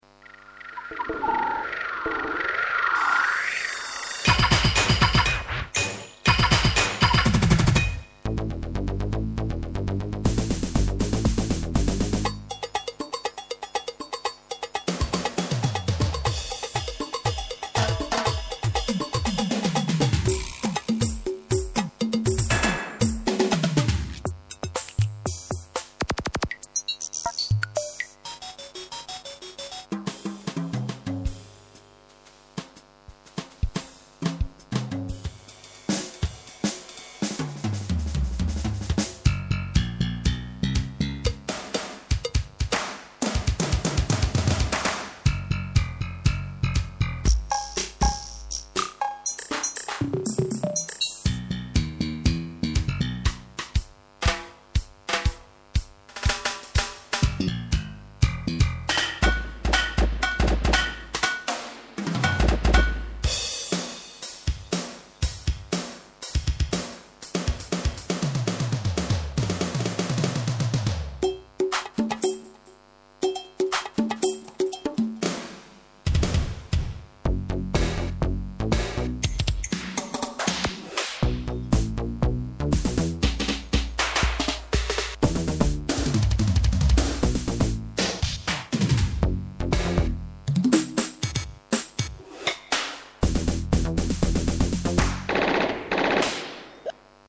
Product : Boss DR-660 Manufacturer : ROLAND Release date : 1992 Country : Japan Comments : Bo�te � rythme avec une dizaine de kits.
dr-660_demosong.mp3